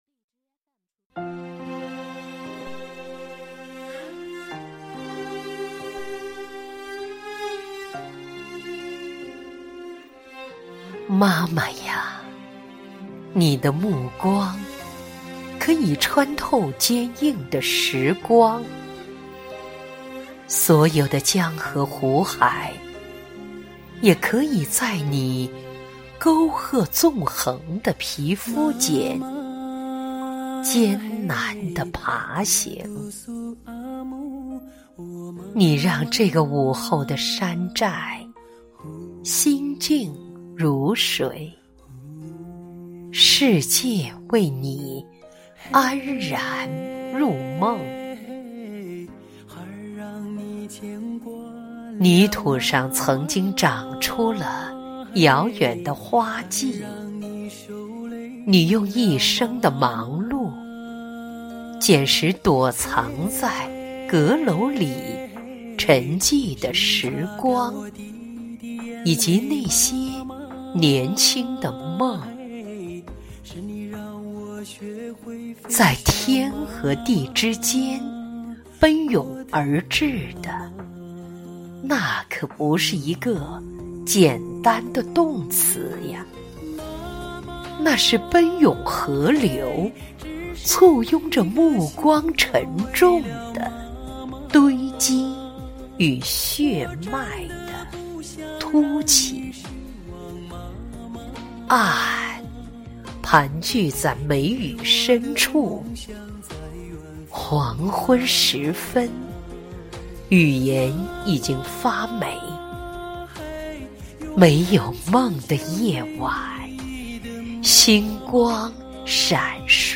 在悠扬舒缓的乐声中，她用深情圆润的声音朗诵着《献给母亲的圣章》。